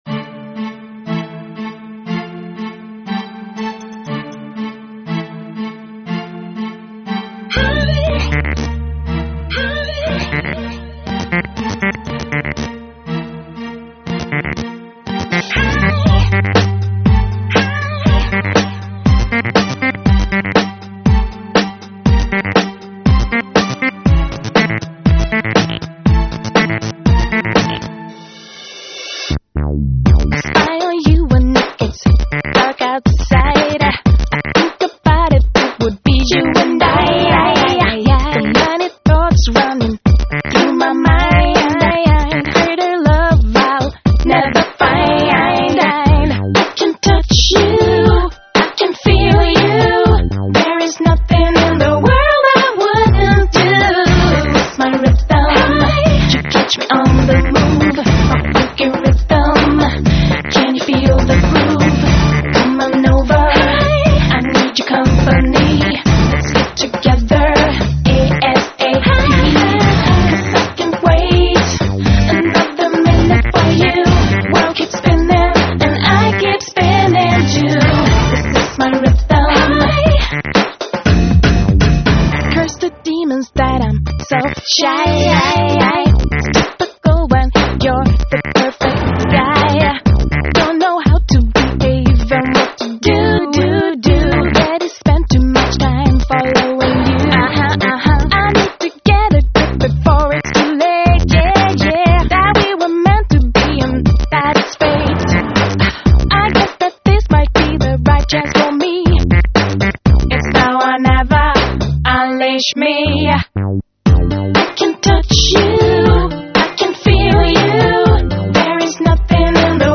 dance/electronic
Fonky Dance tracx !!!!
RnB